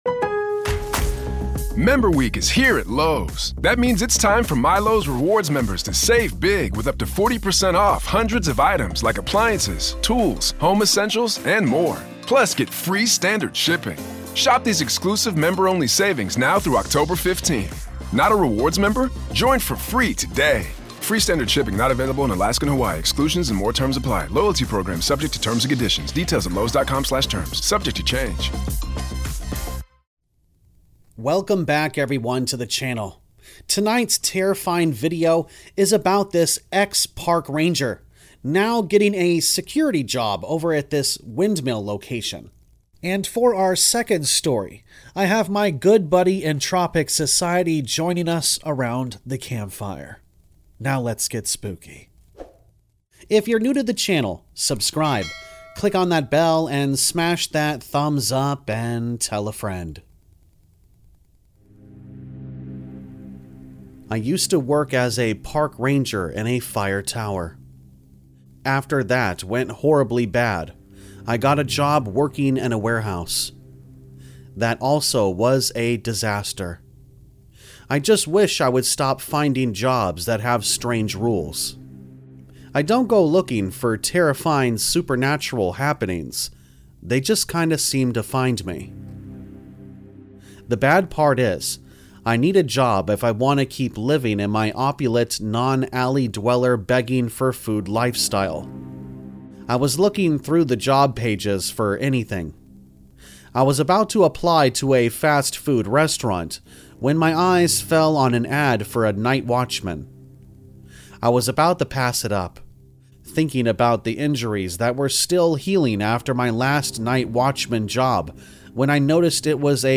All Stories are read with full permission from the authors: Story Credits - 1.